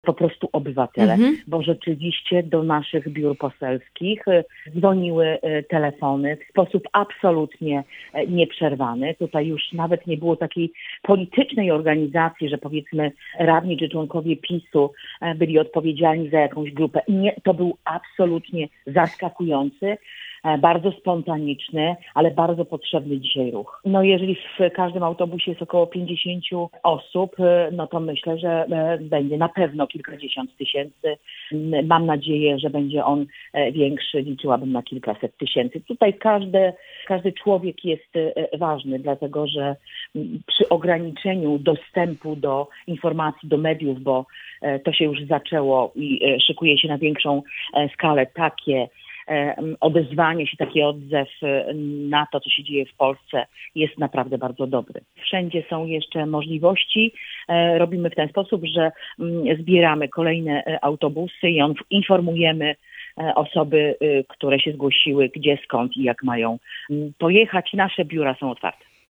Będziemy upominać o praworządność, wolność słowa, konstytucję, a także o osoby Mariusza Kamińskiego i Macieja Wąsika, mówi europoseł Anna Zalewska.